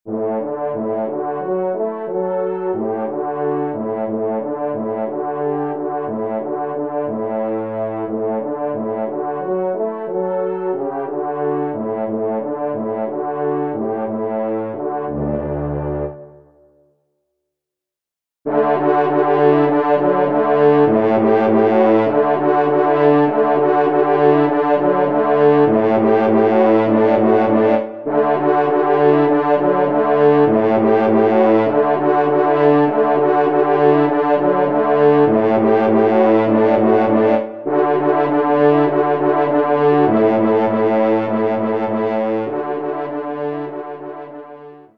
Genre :  Divertissement pour Trompes ou Cors en Ré
5e Trompe